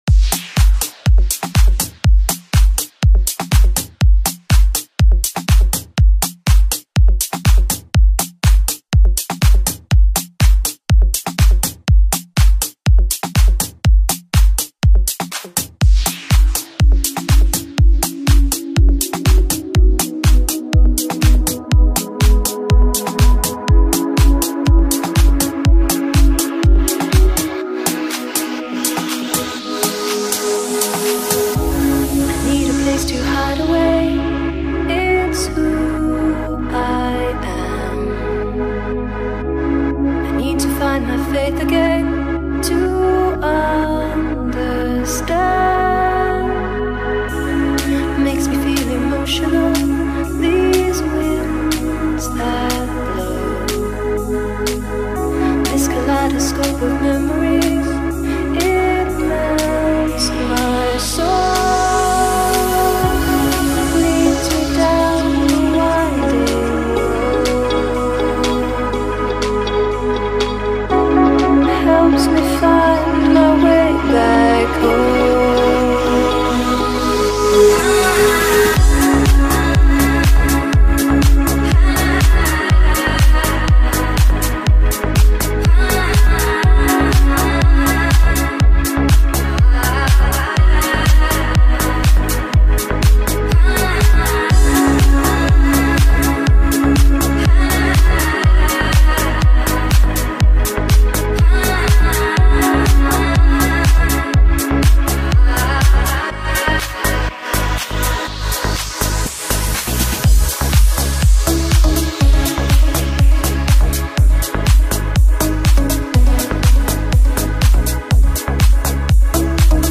Fashion Show Music Background.mp3